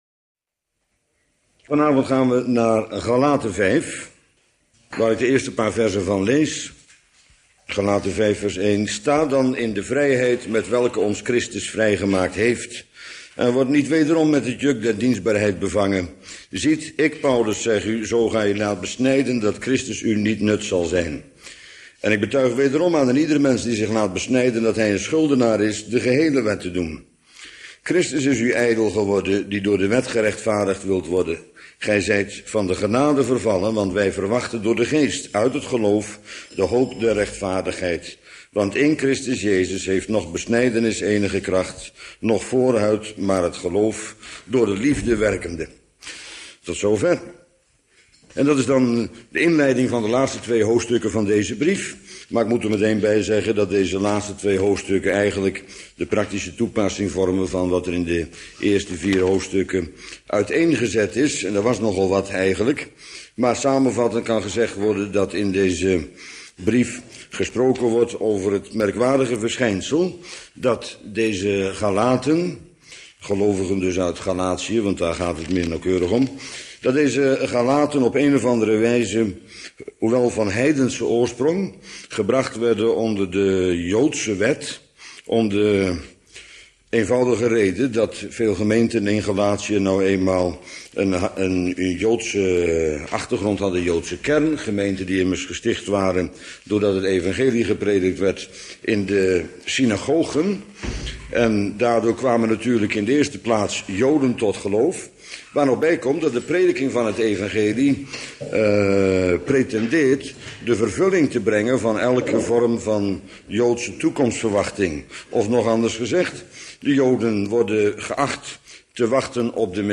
Onderwerp: Wet of Genade. Bijbellezing over Galaten. 5) _______________________________________________________________________